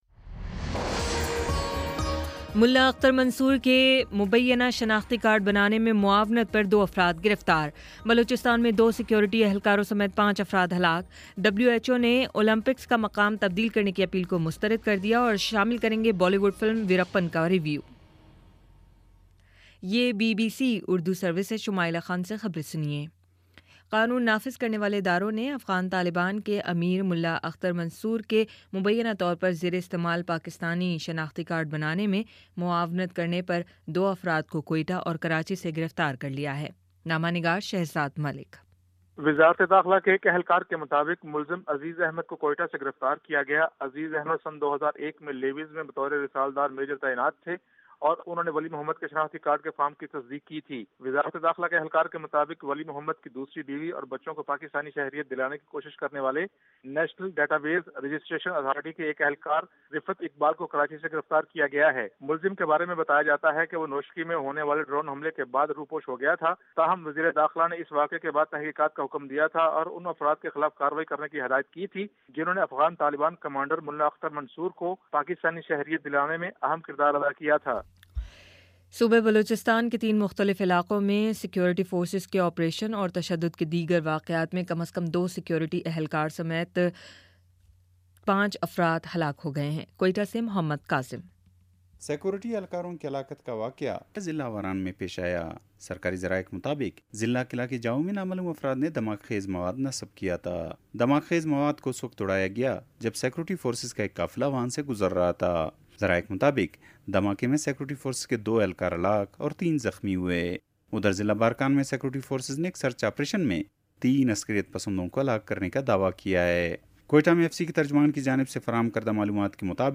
مئی 28 : شام پانچ بجے کا نیوز بُلیٹن